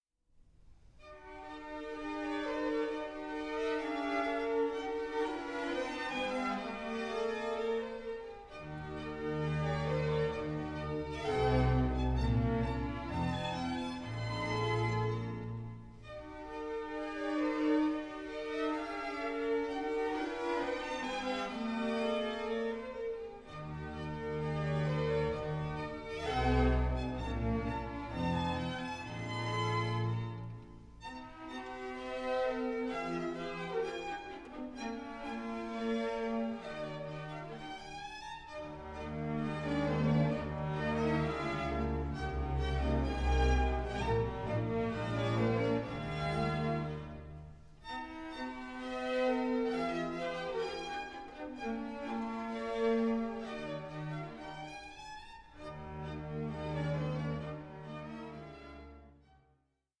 period instruments, transparent, but full of fire.